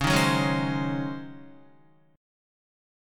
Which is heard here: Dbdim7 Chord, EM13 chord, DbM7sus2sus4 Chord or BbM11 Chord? DbM7sus2sus4 Chord